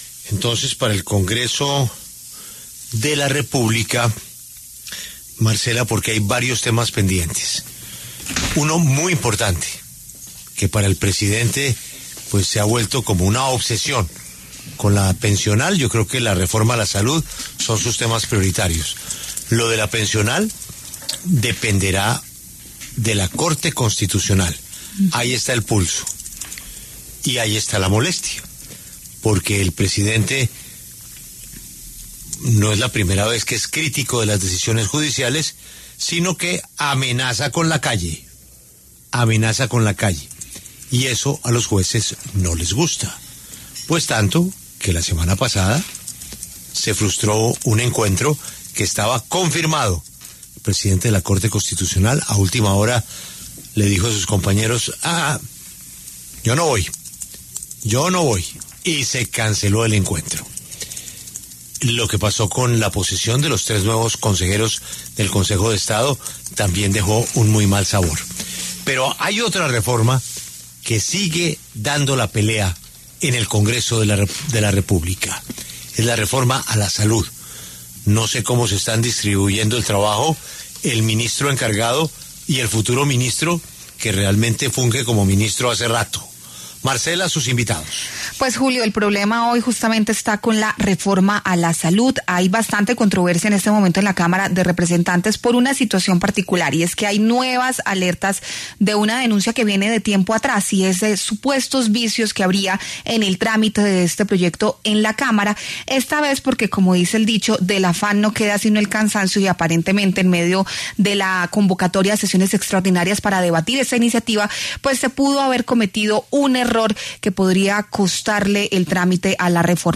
Debate congresistas: reforma a la salud sigue sin agendarse y advierten vicios de trámite
La segunda vicepresidenta de la Cámara, Lina María Garrido, de Cambio Radical, y el representante Alejandro Ocampo, del Pacto Histórico, pasaron por los micrófonos de La W.